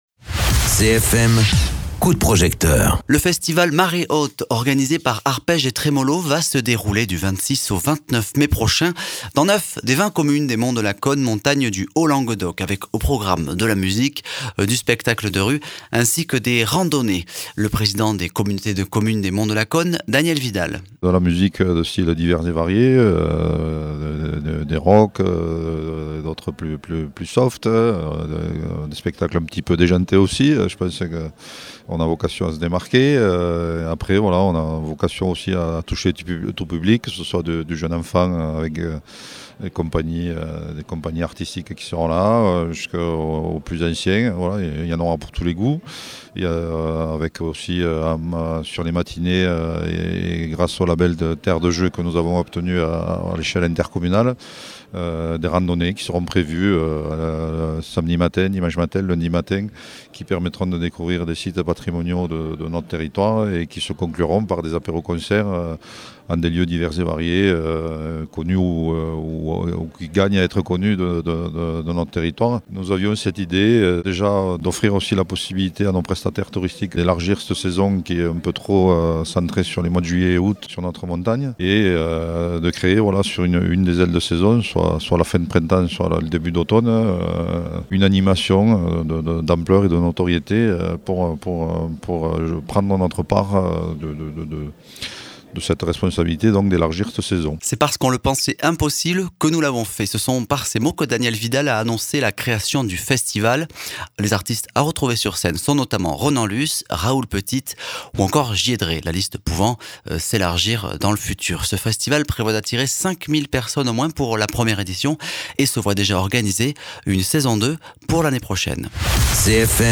Interviews
Invité(s) : Daniel Vidal : président de la communauté de commune des Monts de Lacaune Montagne du Haut-Languedoc